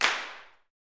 CLAPSUTC6.wav